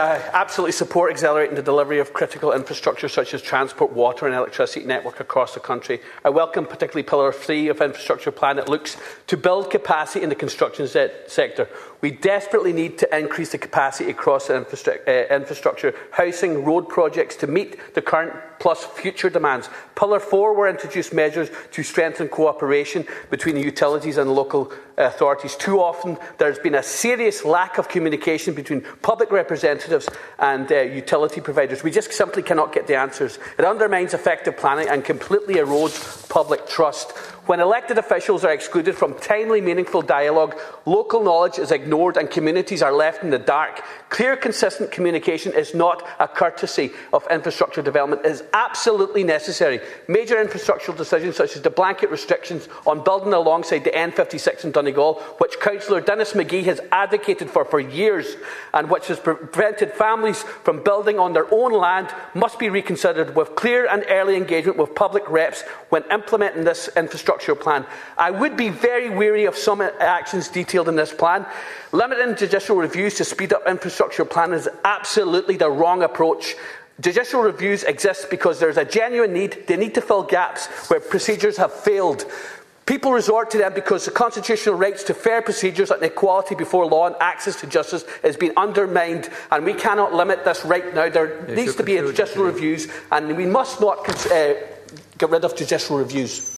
Deputy Charles Ward told the Dail this week that judicial reviews serve to ensure that genuine concerns are heard.